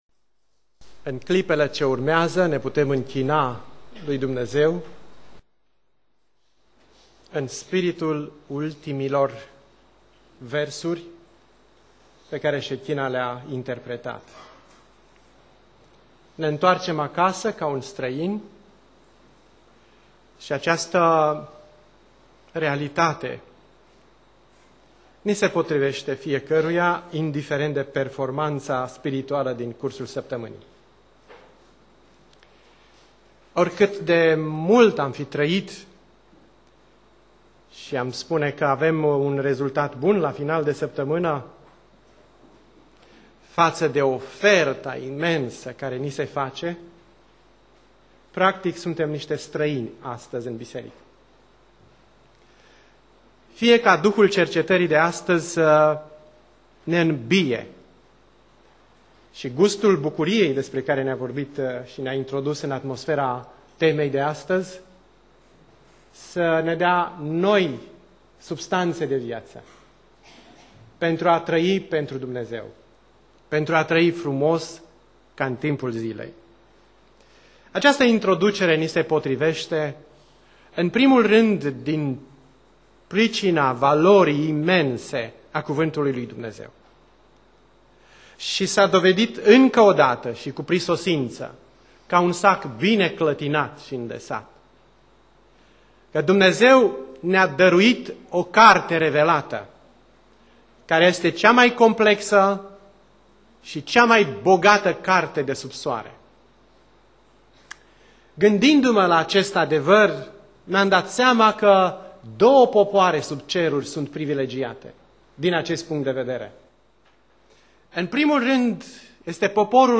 Predica Exegeza Eclesiastul Recapitulare